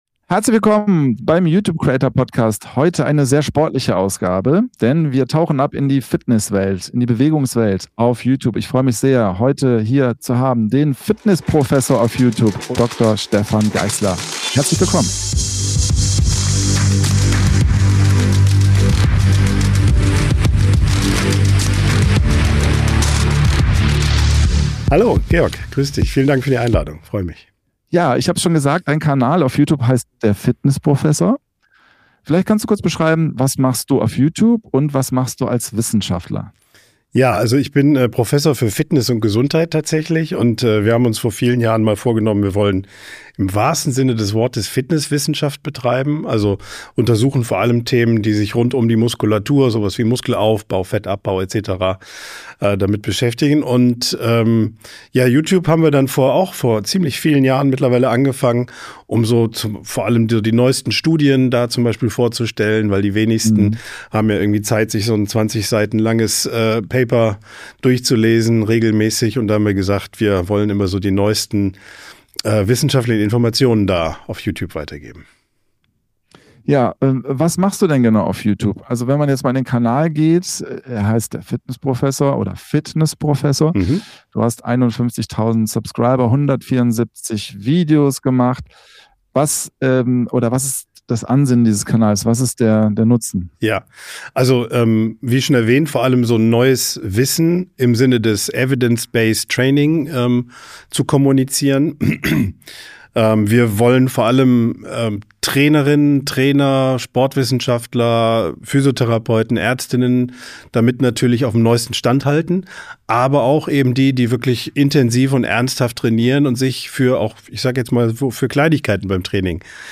Taucht ein in ein interessantes, unterhaltsames und offenes Gespräch mit Fynn Kliemann
direkt im Kliemannsland. Fynn Kliemann erzählt von seinen frühen YouTube-Anfängen am 30. August 2009 mit dem Kanal "X Most Important".